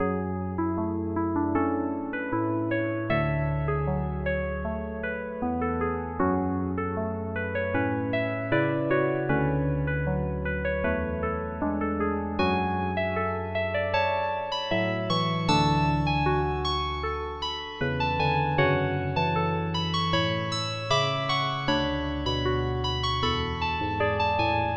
多汁世界的情感悲伤循环
标签： 155 bpm Hip Hop Loops Rhodes Piano Loops 4.17 MB wav Key : F FL Studio
声道立体声